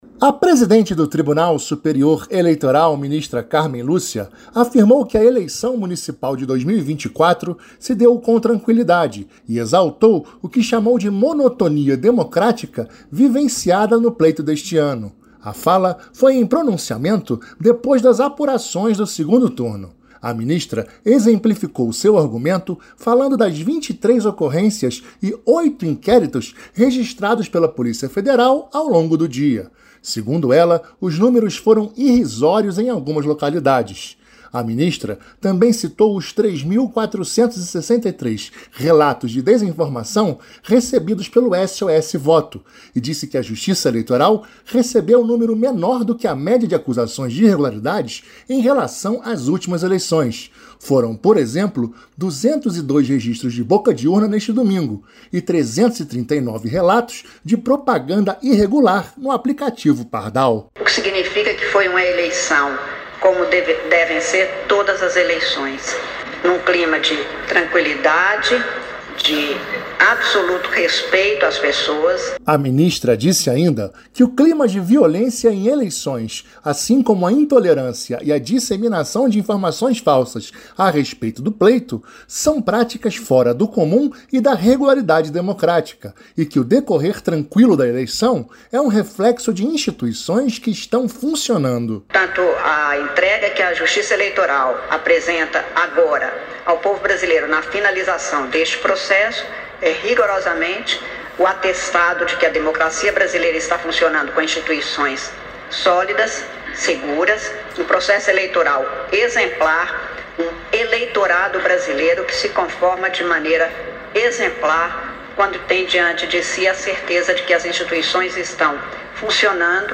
A fala foi na sede do TSE, em pronunciamento após a definição dos nomes dos eleitos. A ministra exemplificou seu argumento falando que foram apenas 23 ocorrências e oito inquéritos registrados pela Polícia Federal ao longo do dia.
Pronunciamento